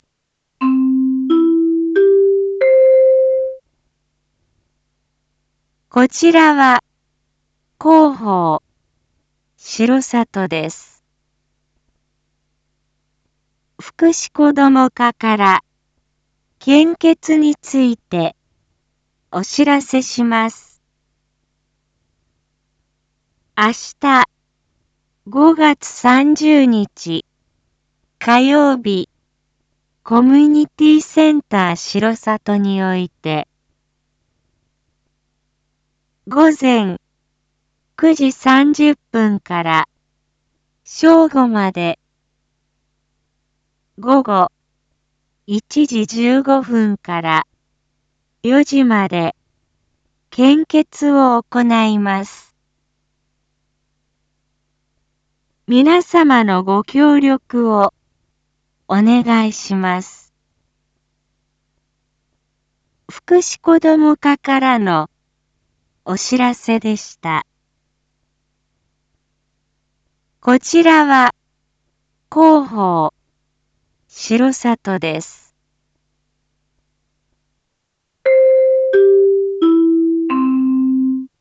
Back Home 一般放送情報 音声放送 再生 一般放送情報 登録日時：2023-05-29 19:01:22 タイトル：Ｒ5年.5.29 19時放送分 インフォメーション：こちらは広報しろさとです。 福祉こども課から献血についてお知らせします。